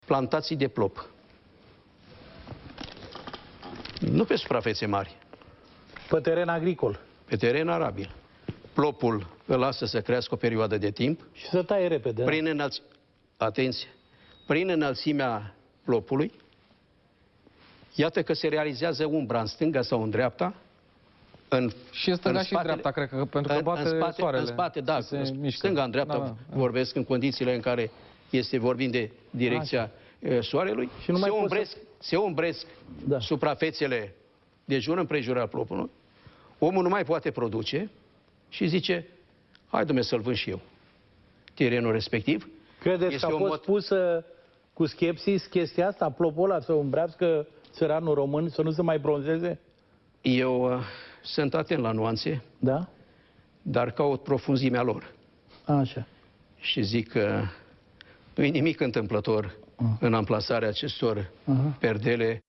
Marți seară, într-o emisiune la postul public de televiziune, Petre Daea a explicat că plopii au efecte dăunătoare asupra agriculturii.
Petre-Daea-la-TVR.mp3